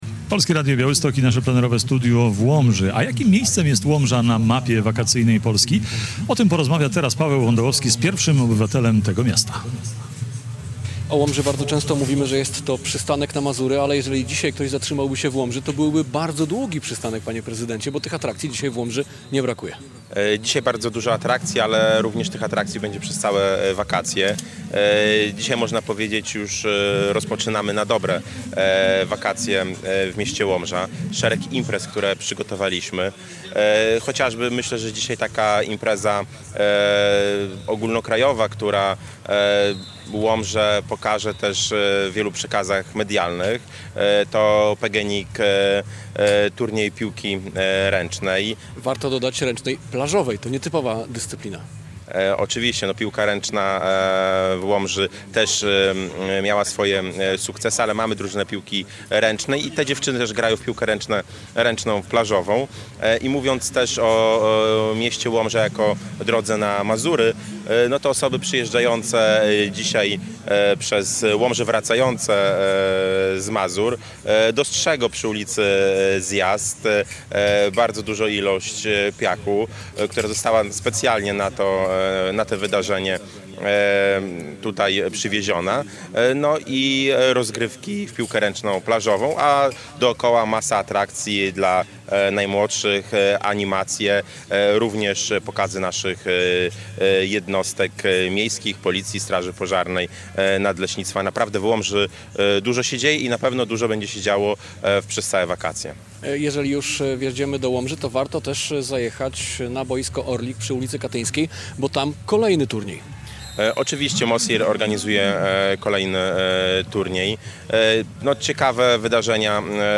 W niedzielę (4.07) nasze studio przenieśliśmy do Łomży. Mogliście znaleźć nas na dawnej muszli koncertowej przy ulicy Zjazd.
Z prezydentem Łomży rozmawia
Nasz radiowy program podczas akcji "Podlaskie To Tu!" nadawaliśmy z tego samego miejsca, gdzie trwały rozgrywki PGNiG Summer Superligi, czyli w plażowej piłce ręcznej.